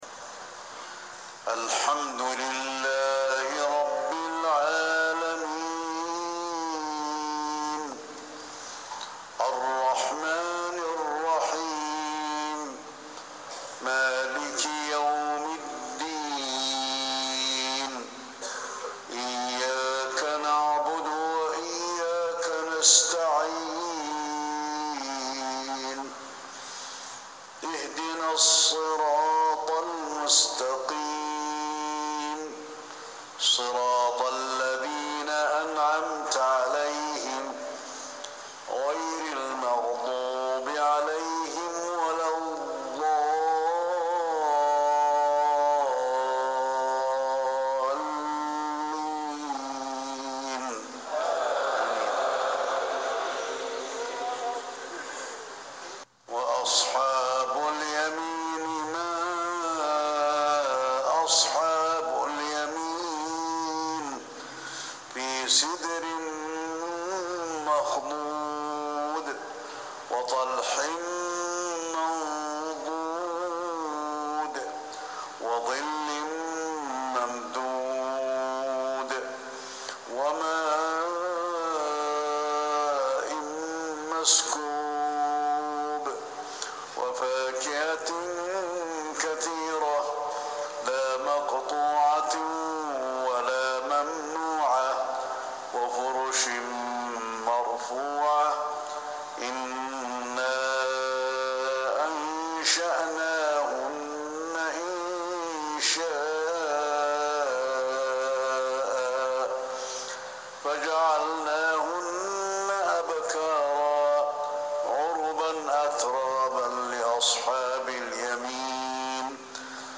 صلاة العشاء 3-6-1440هـ من سورة الواقعة 27-74 | Isha 8-2-2019 prayer from Surat Alwaqi`ah > 1440 🕌 > الفروض - تلاوات الحرمين